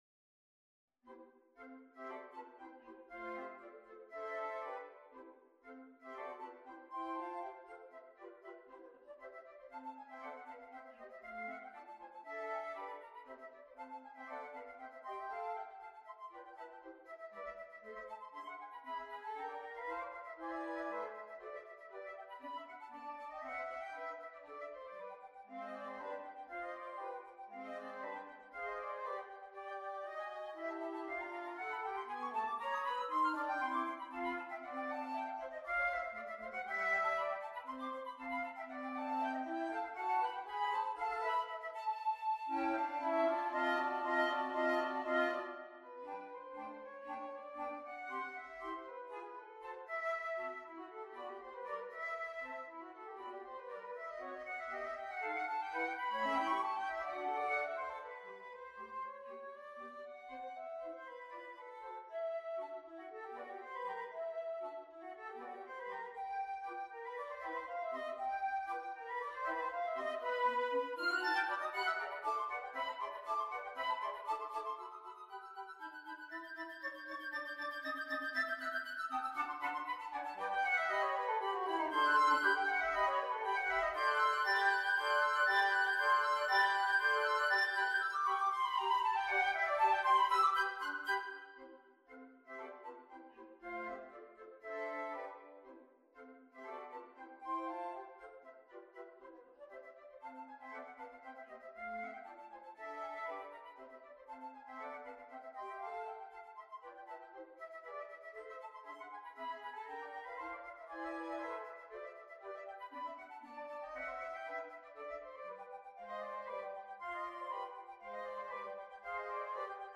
Flute 1Flute 2Flute 3Alto Flute
2/4 (View more 2/4 Music)
Allegro giusto = 118 (View more music marked Allegro)
Flute Quartet  (View more Advanced Flute Quartet Music)
Classical (View more Classical Flute Quartet Music)